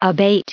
added pronounciation and merriam webster audio
4_abate.ogg